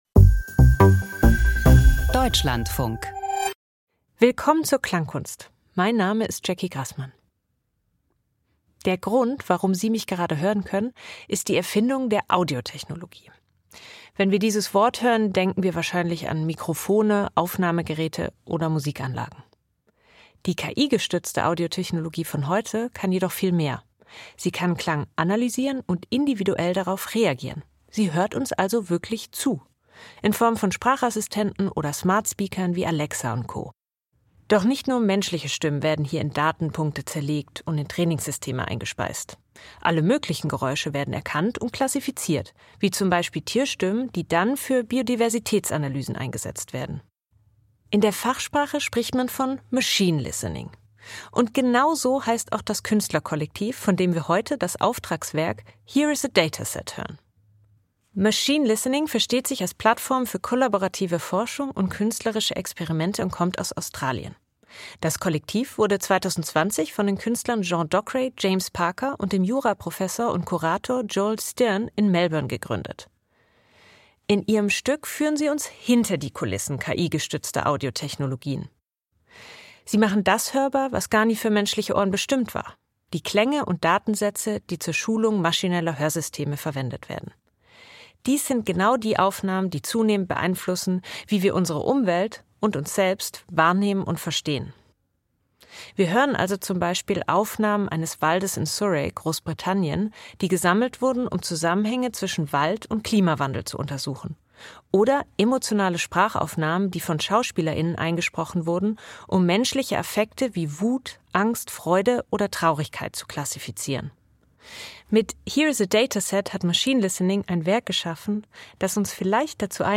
Wie klingt eine traurige Stimme?
Hörstück aus Trainingsdaten für künstliche Intelligenz.
Klangkunst Hörstück mit KI-Trainingsdaten Here is a dataset 38:39 Minuten Dieses Hörstück basiert auf fünf Datensätzen, die normalerweise zum Trainieren von KI-Systemen verwendet werden.